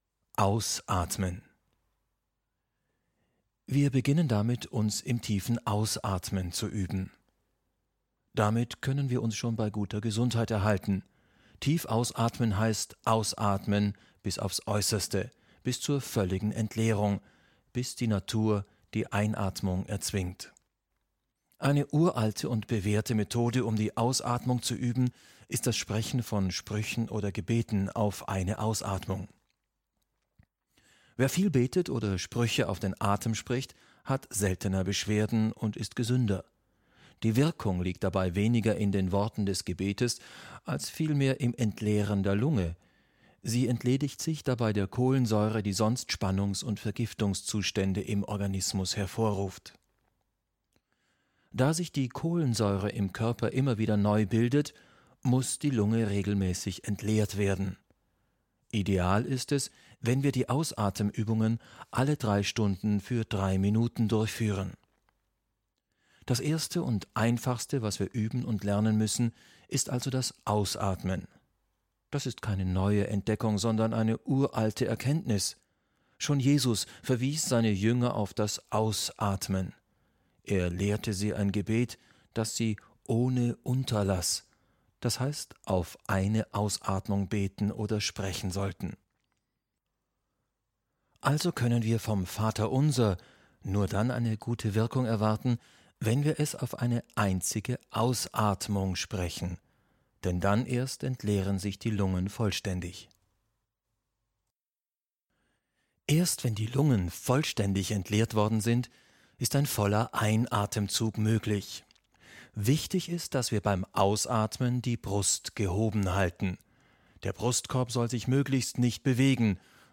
(Gefällt Ihnen der gesprochene Text? Haben Sie Interesse an einem kompletten Hörbuch der Atemkunde? Schreiben Sie uns: Kontakt )